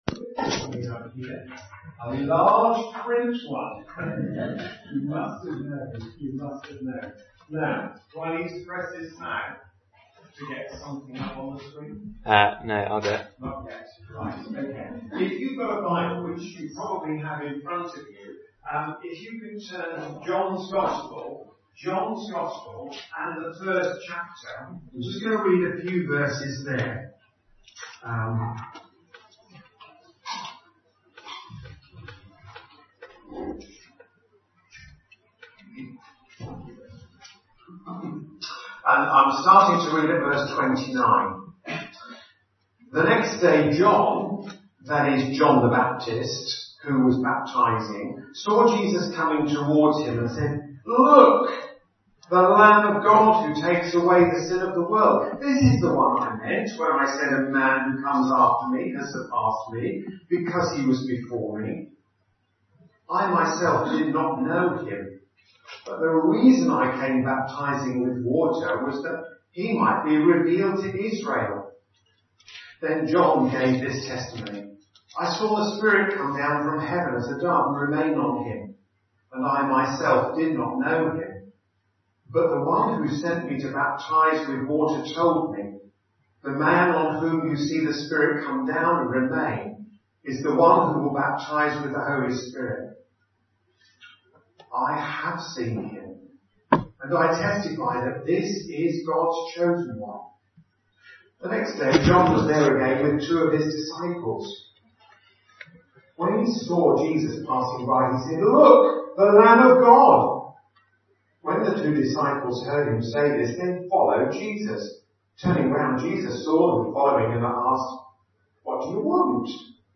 Sermons
Visiting speaker